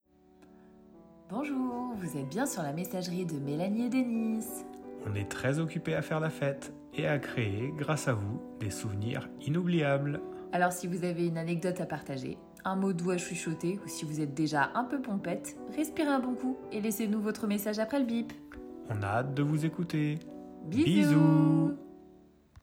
greeting.wav